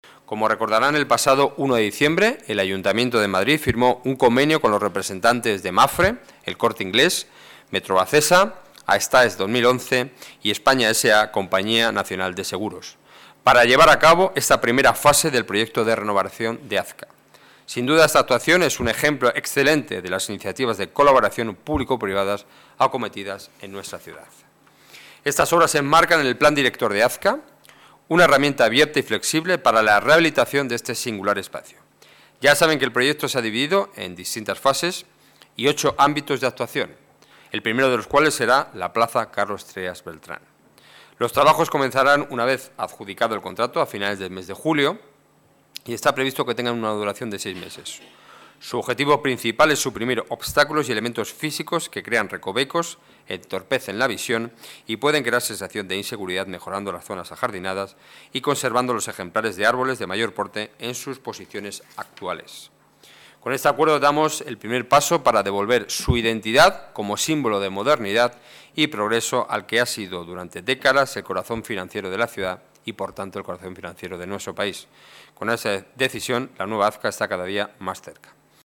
Nueva ventana:Declaraciones portavoz del Gobierno municipal, Enrique Núñez: Junta de Gobierno, Azca